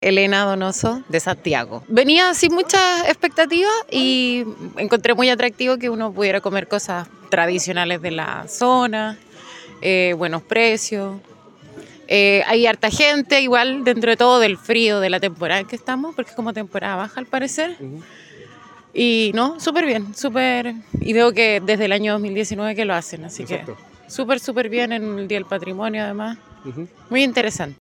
una turista proveniente de Santiago